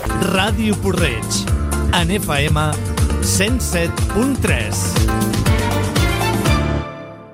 Indicatiu de l'emissora.